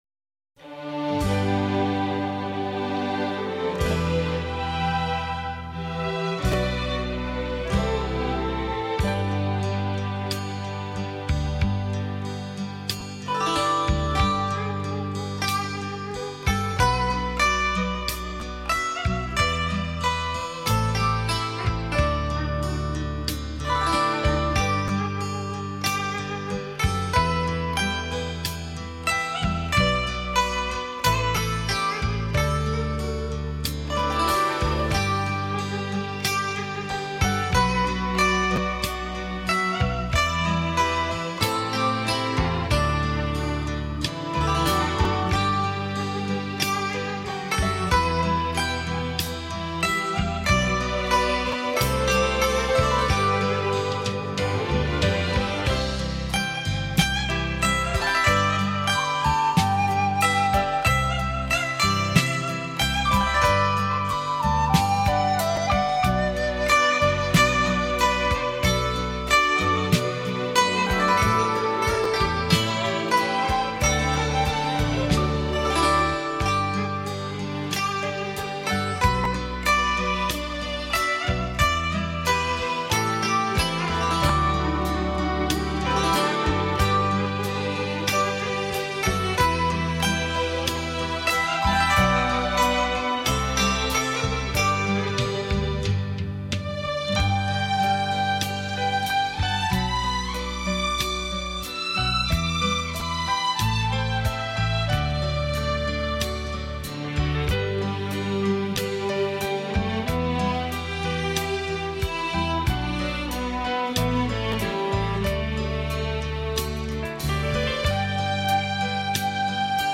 古筝高雅、古朴，音色优美，既有大气磅礴也有小桥流水，透着一股超凡脱俗的古典美。